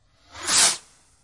烟花 " 瓶装火箭08
描述：使用Tascam DR05板载麦克风和Tascam DR60的组合使用立体声领夹式麦克风和Sennheiser MD421录制烟花。我用Izotope RX 5删除了一些声音，然后用EQ添加了一些低音和高清晰度。
Tag: 高手 焰火 裂纹